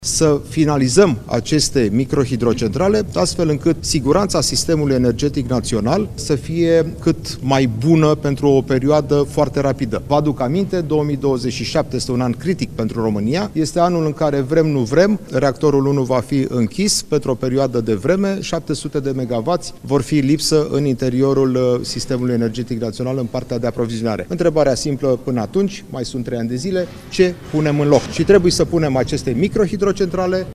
Senatorul Radu Oprea, purtător de cuvânt al PSD, speră ca proiectul să treacă rapid de Parlament: